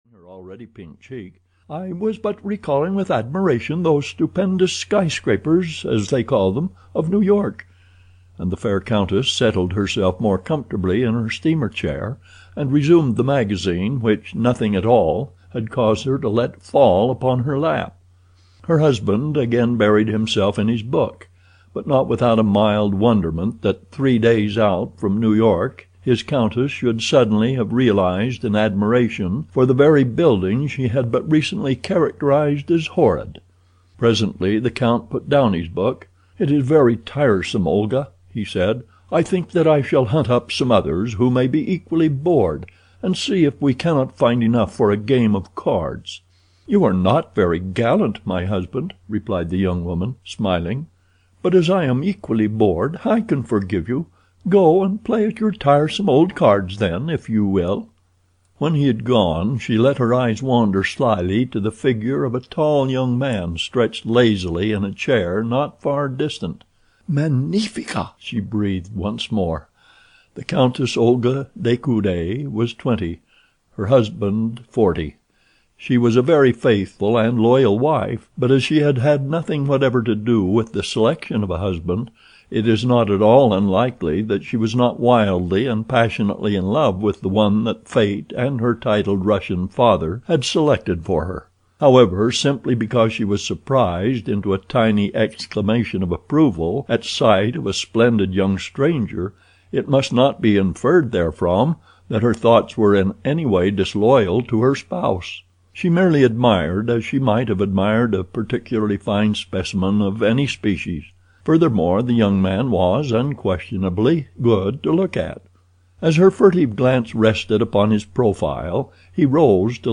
The Return of Tarzan (EN) audiokniha
Ukázka z knihy